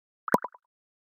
Звуки Gmail уведомлений скачать - Zvukitop